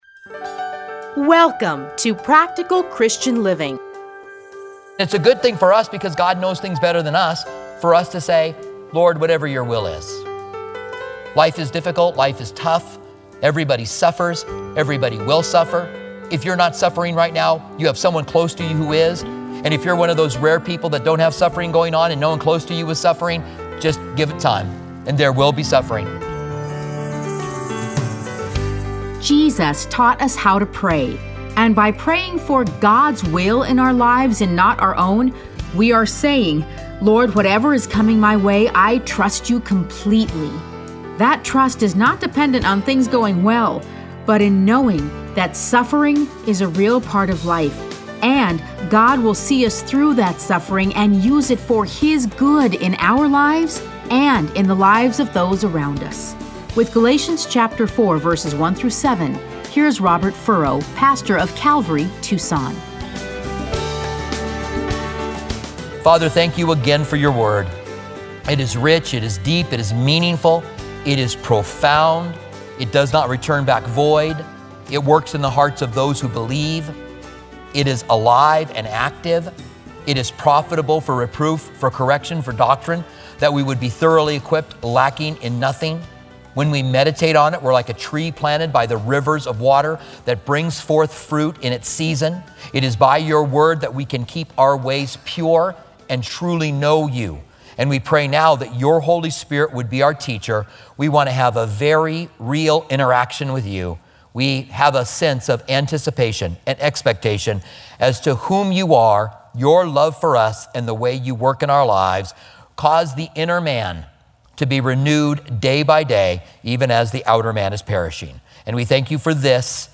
Listen to a teaching from Galatians 4:1-7.